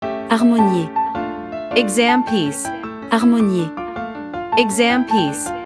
• 人声数拍